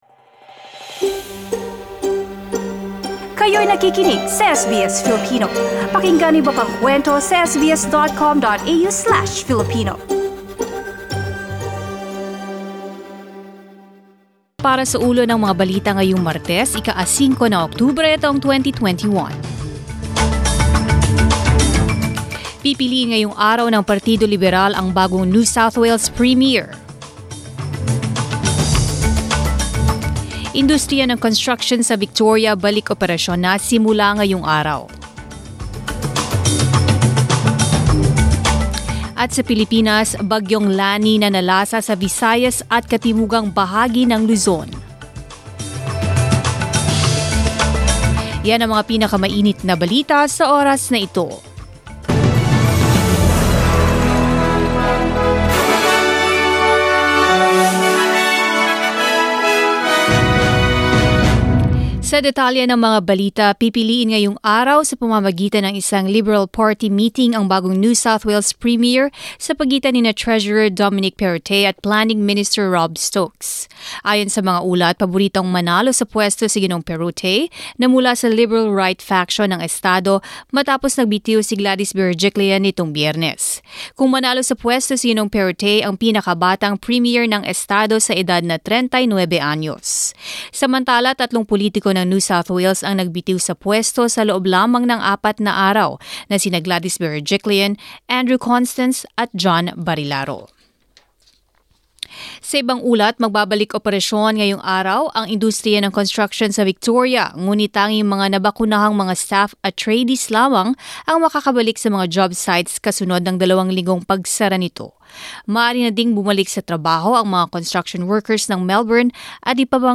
Mga Balita ngayong ika-5 ng Oktubre